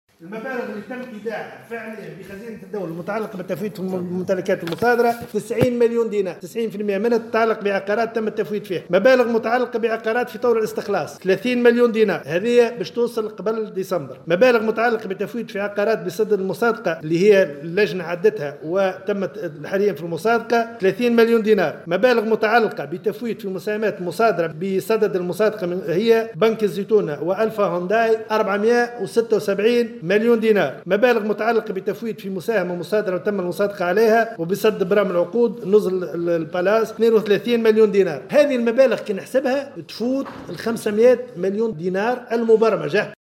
أكد وزير المالية رضا شلغوم اليوم الخميس في تصريح لمراسل الجوهرة "اف ام" أن المبالغ التي تم ايداعها الى حد الان في خزينة الدولة والمتعلقة بالتفويت في أملاك مصادرة 90 مليون دينار .